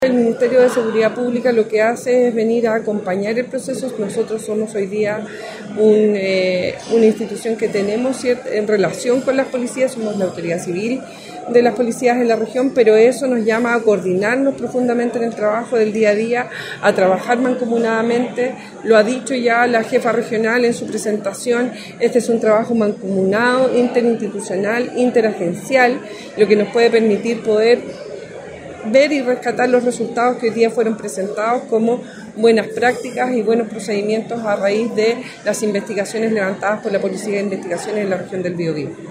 En el marco del aniversario número 92 de la institución, y con la presencia del director nacional, Eduardo Cerna, la Policía de Investigaciones en el Biobío realizó la Cuenta Pública de Gestión Policial correspondiente al último año.